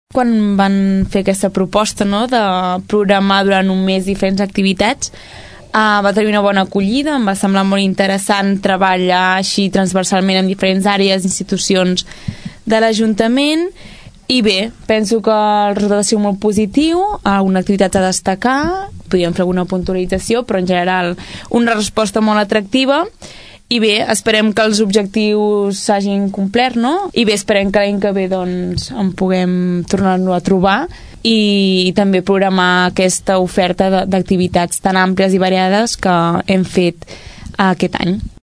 Aquest passat divendres, el programa matinal Ara i Aquí de Ràdio Tordera va acollir una taula rodona sota el títol “Què vol dir tenir salut?”.